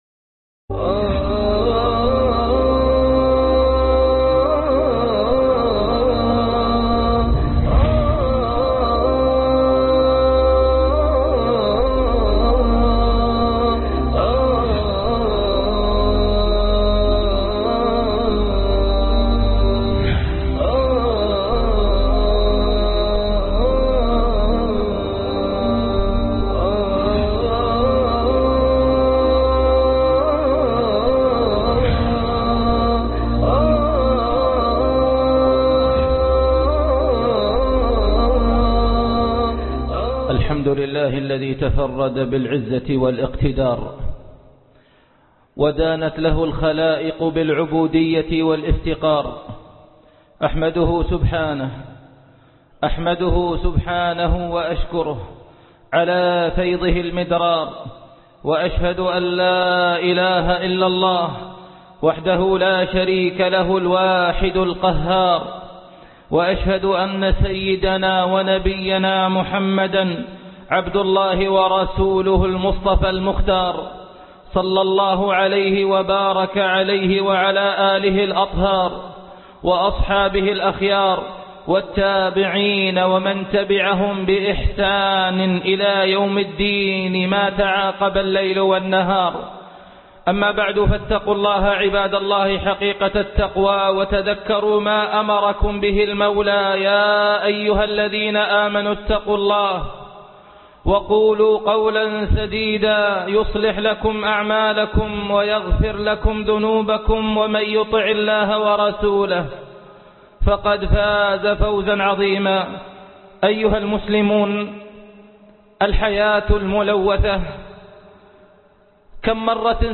الحياء الملوث - خطب الجمعة